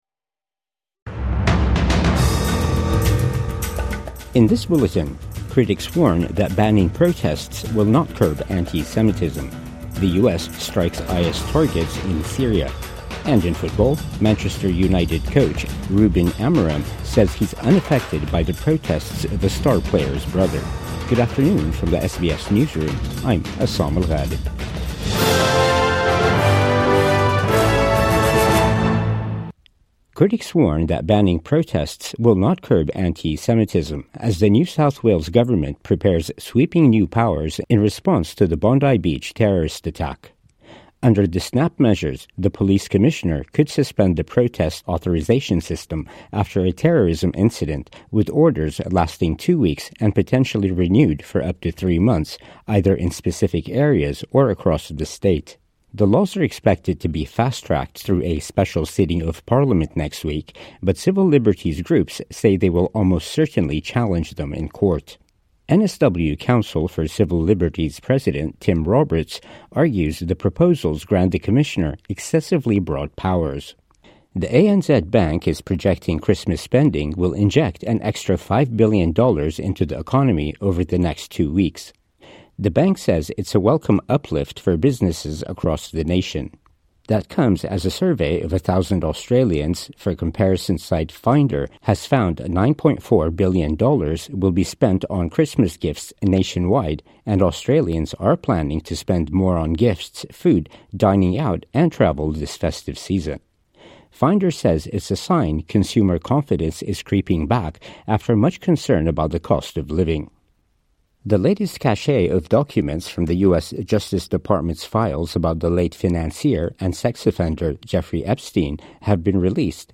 Critics warn banning protests will not curb antisemitism | Midday News Bulletin 20 December 2025